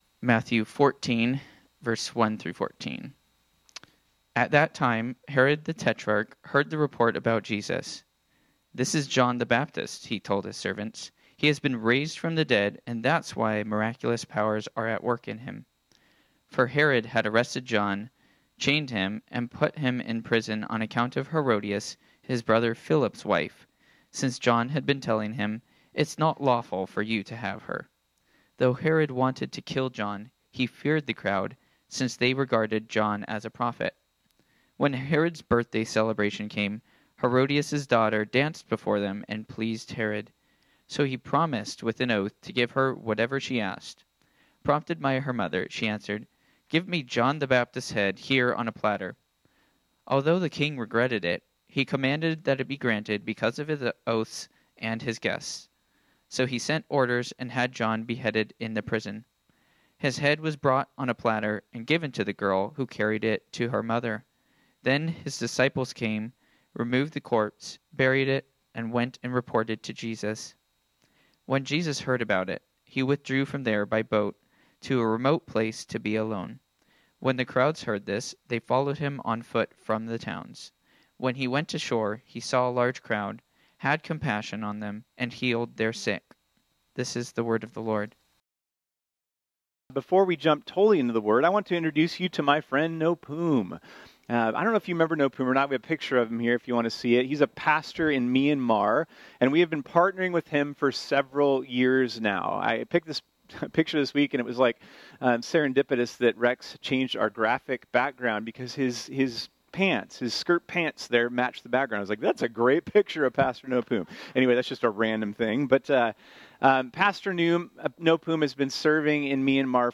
This sermon was originally preached on Sunday, June 23, 2024.